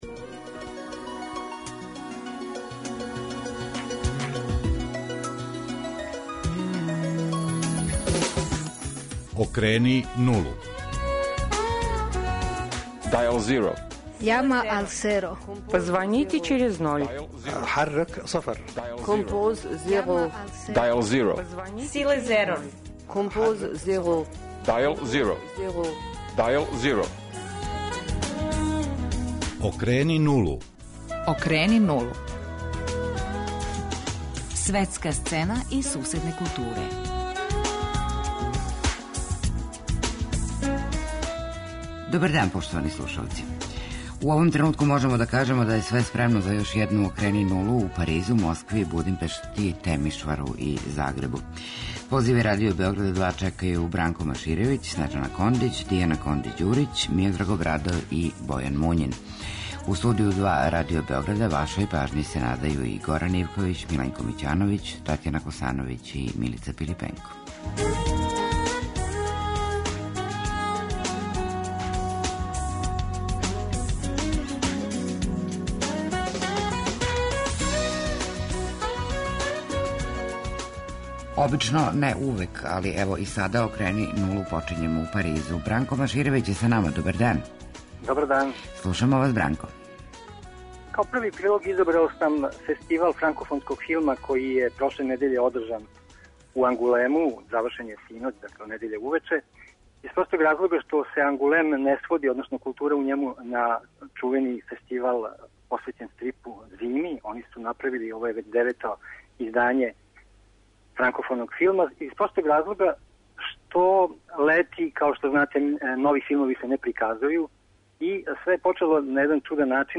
У Београду, у студију Радио Београда 2